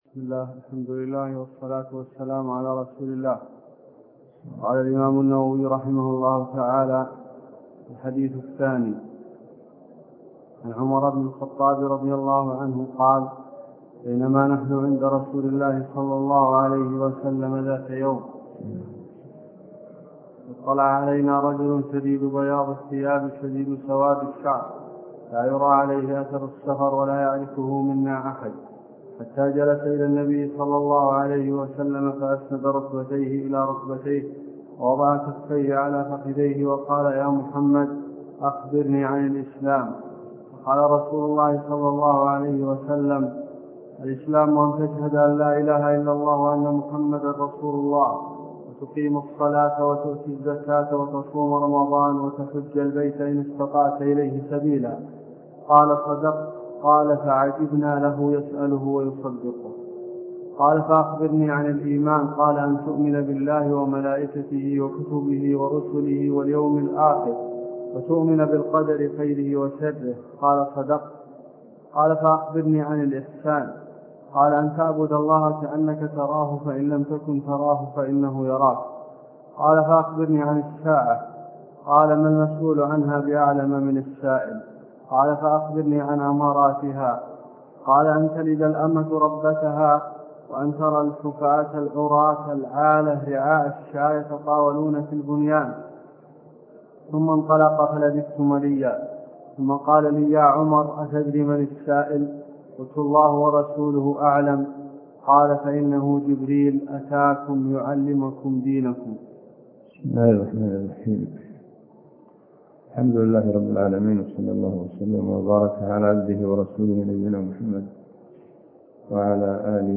عنوان المادة الدرس (4) شرح الأربعين النووية تاريخ التحميل الأحد 22 يناير 2023 مـ حجم المادة 18.34 ميجا بايت عدد الزيارات 286 زيارة عدد مرات الحفظ 108 مرة إستماع المادة حفظ المادة اضف تعليقك أرسل لصديق